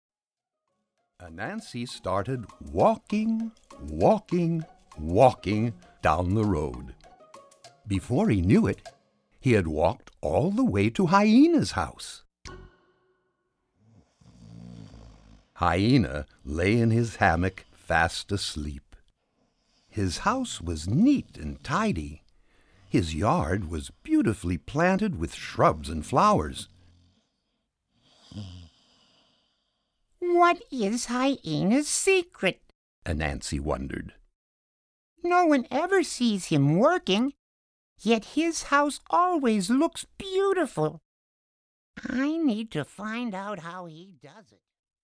Readalongs